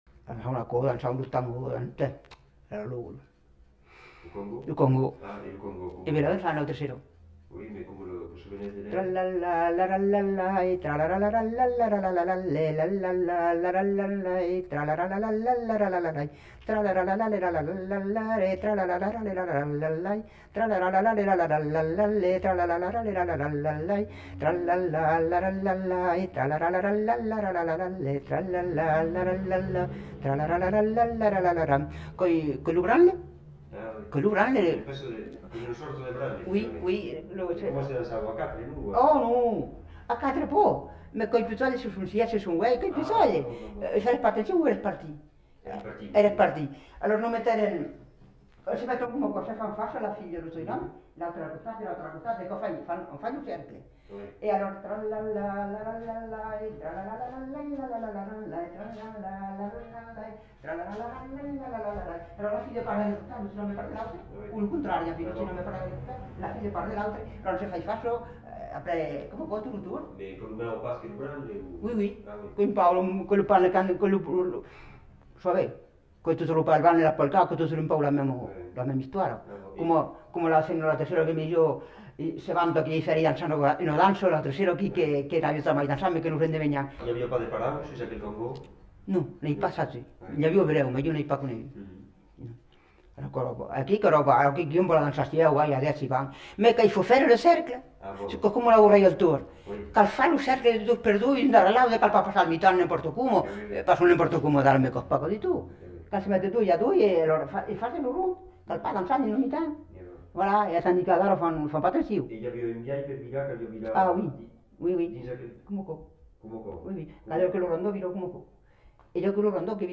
Lieu : Castillonnès
Genre : chant
Effectif : 1
Type de voix : voix de femme
Production du son : fredonné
Danse : congo